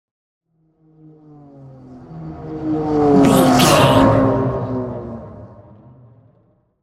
Pass by vehicle engine deep
Sound Effects
pass by
car